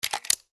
take_ammo.wav